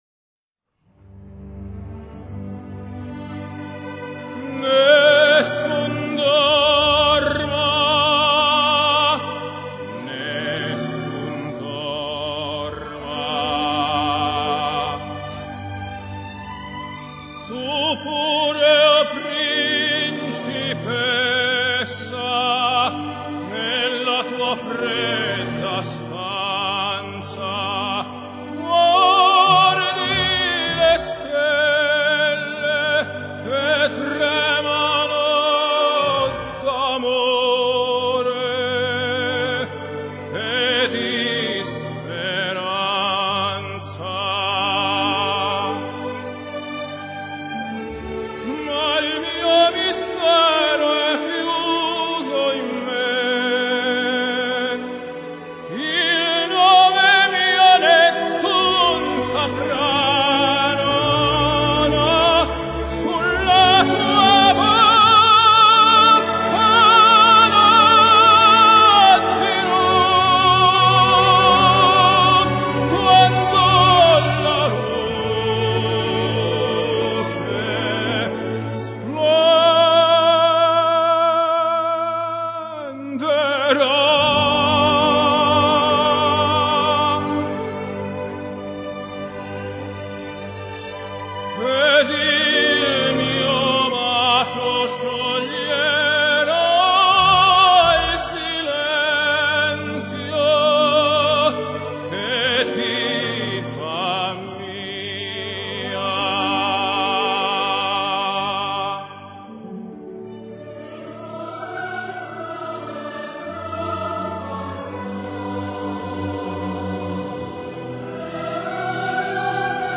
一開唱就爽到打舌打不停